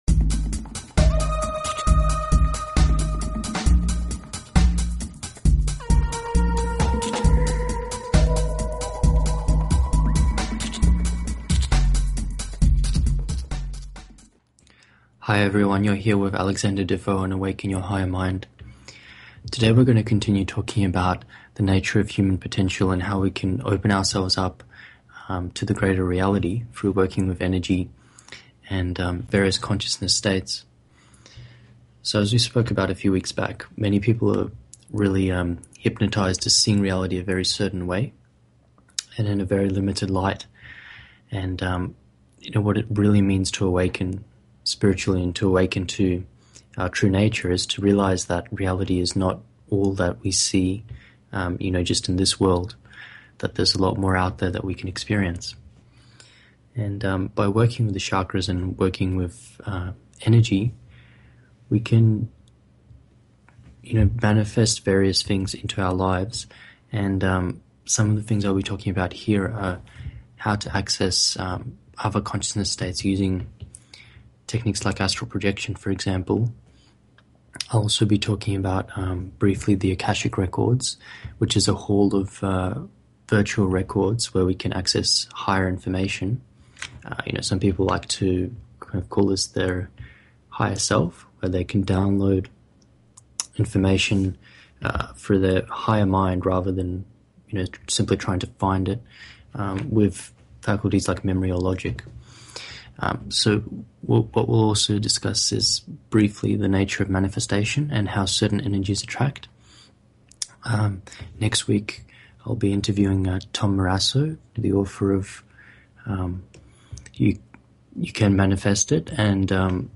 Talk Show Episode, Audio Podcast, Awaken_Your_Higher_Mind and Courtesy of BBS Radio on , show guests , about , categorized as
The program will often feature discussions, tutorials and interviews in the areas of practical self awareness.